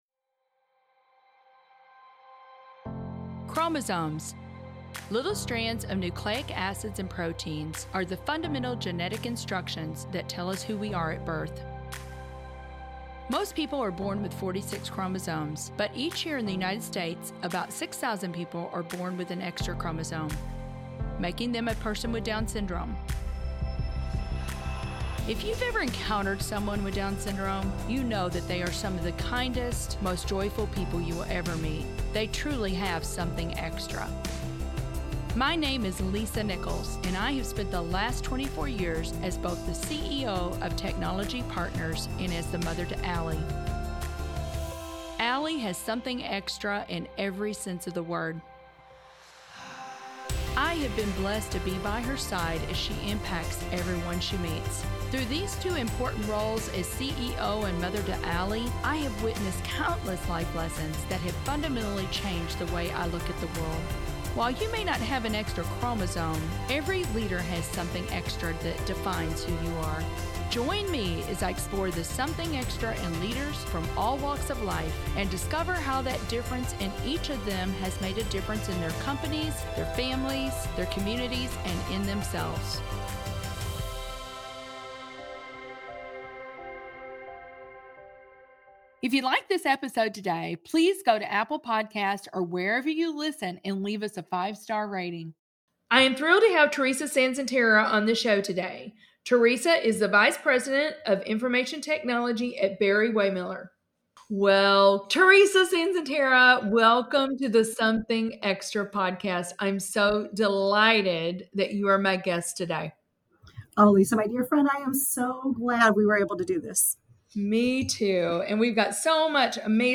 Tune in to hear an inspiring conversation around growth mindset, personal narratives, and this year's St. Louis HMG CIO Summit.